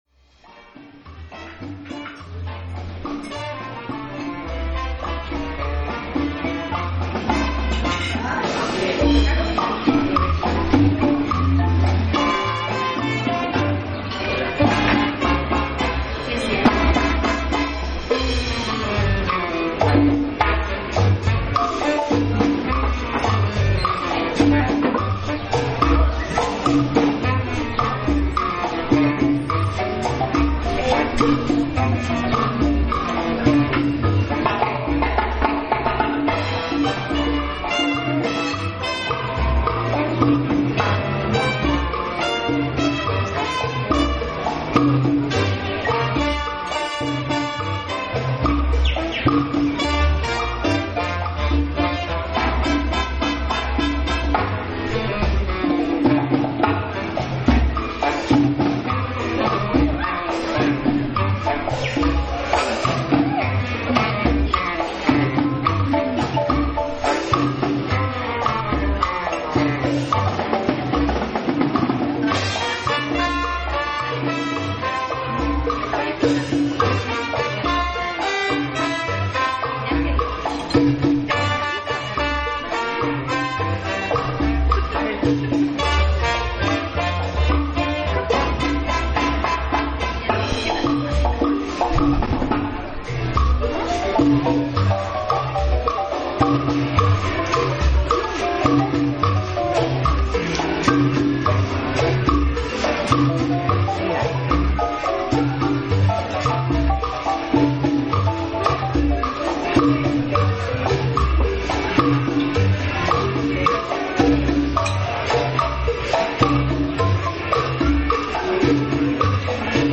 Marimba orquesta en el parque
Es de tarde, la orquesta de marimba se encuentra en el centro de uno de los pequeños parques con que cuenta la ciudad de San Cristóbal de Las Casas.
Turistas y vecinos del lugar se acercan, unos observan atentos la ejecución de los instrumentos, otros sentados en las bancas escuchan la música y una que otra pareja se anima a bailar cerca de ella.